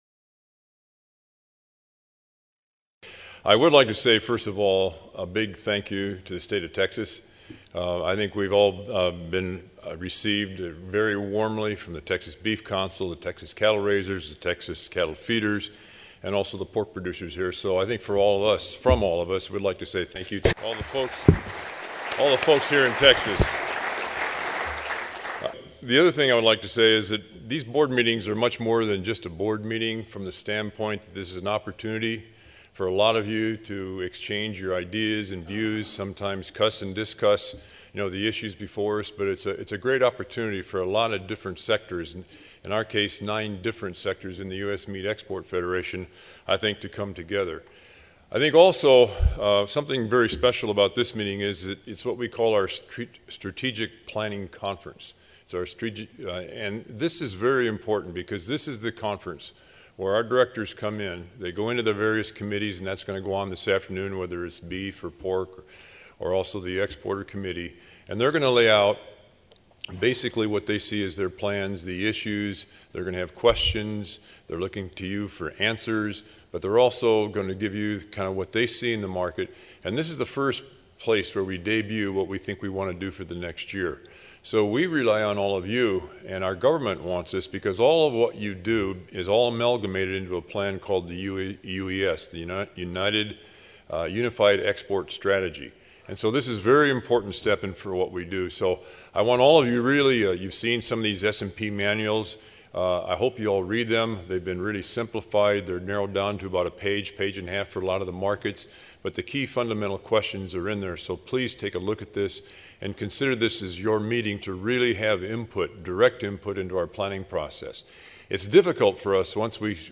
USMEF News: Presidential Address, Distinguished Service Award Highlight USMEF Strategic Planning Conference